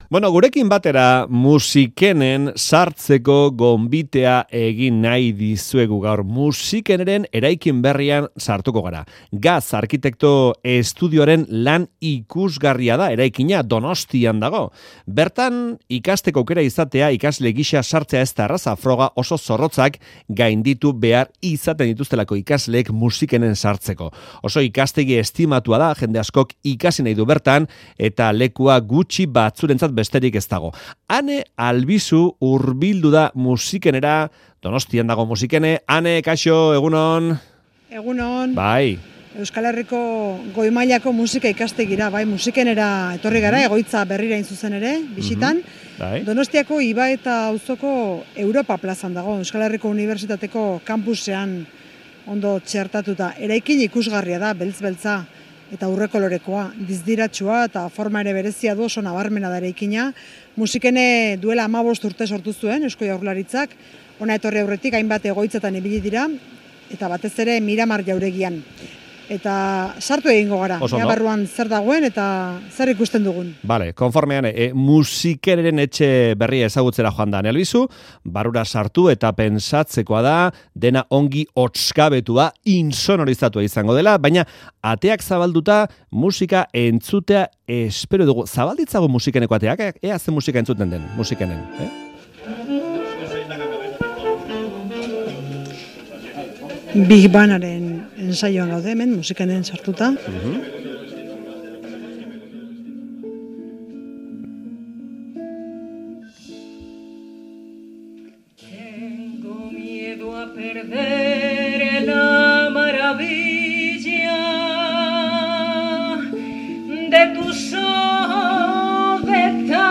Audioa: Musikaz bizitzea amets duten ikasleekin hizketan aritu gara Euskadi Irratiko Faktorian; Musikeneren eraikin berrian egin dugu hitzordua.